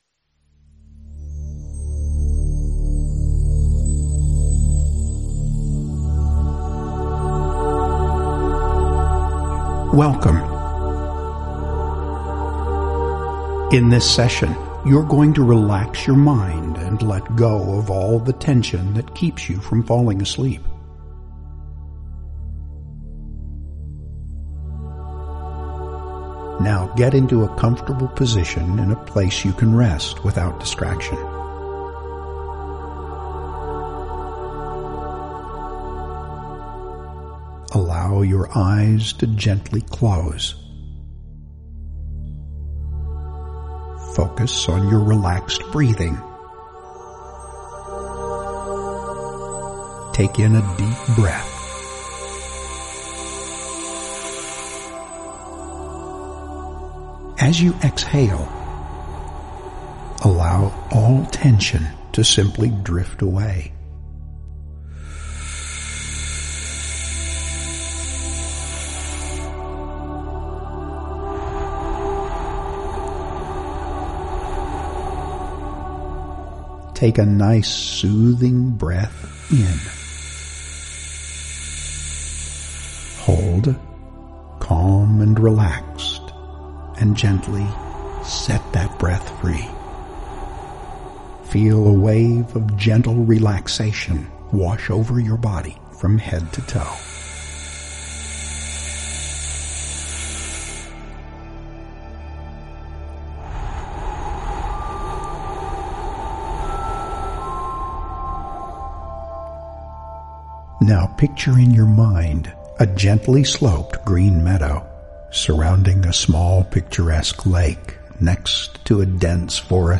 Tonight when you are ready for bed, pop in your earbuds, click on the audio clip below and relax as you close your eyes and listen to a small portion of our “Beside Quiet Waters” sleep session that will reinforce today’s devotional as you drift off to sleep. Note that while the relaxation portion is similar, the message is different.